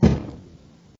airutils_collision.ogg